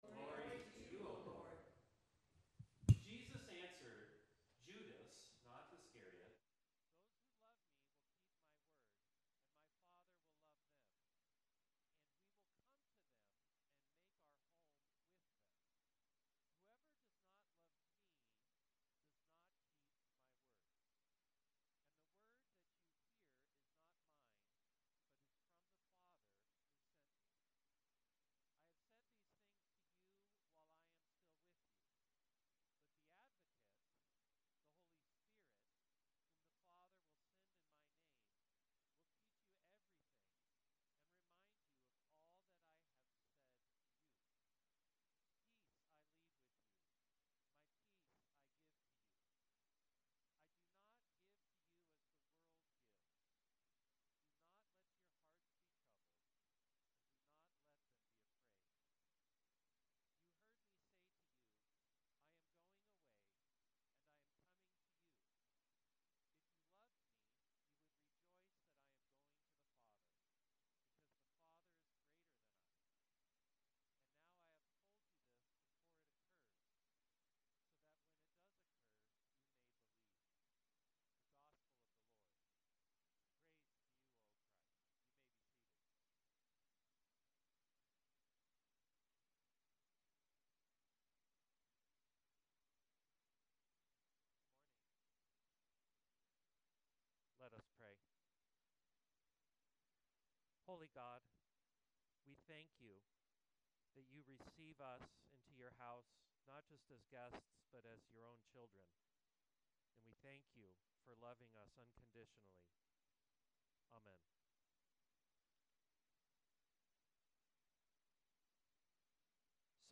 Sermon 05.25.25